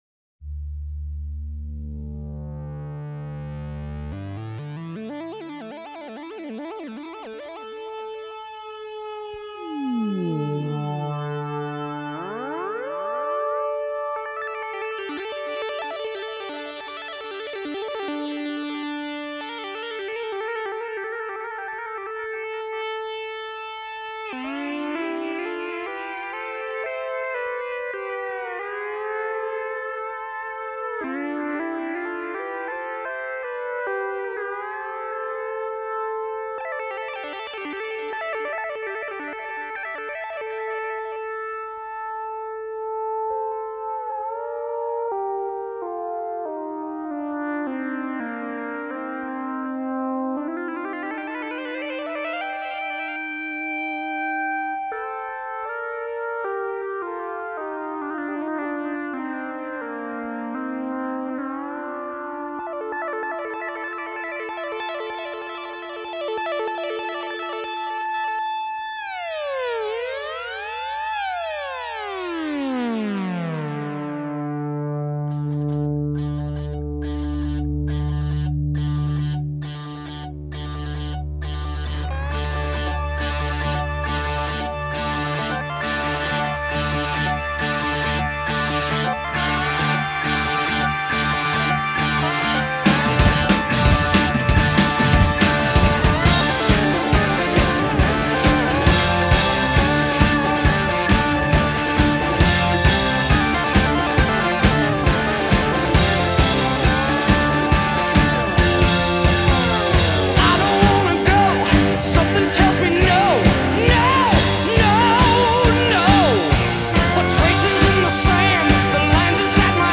Жанр: Хард-рок